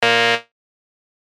ブー
/ F｜演出・アニメ・心理 / F-10 ｜ワンポイント マイナスイメージ_
不正解 間違い